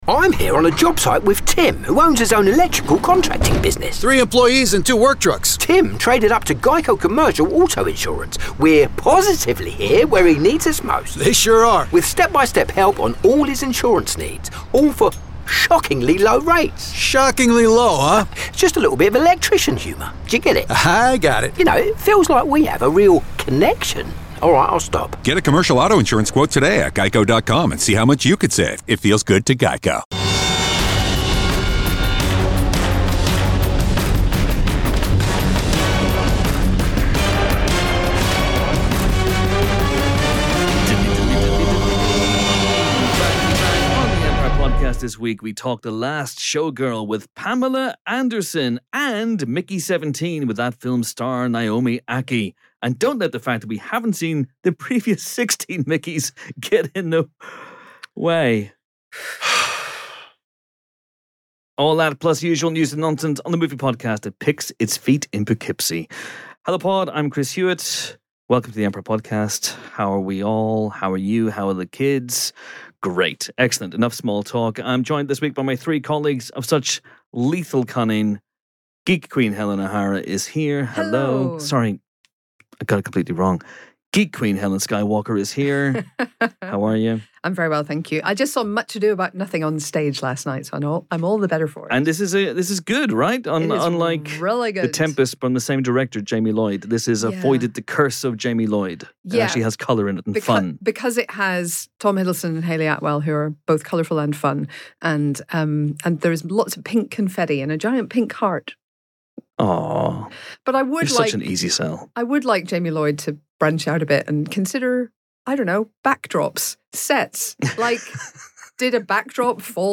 Film Reviews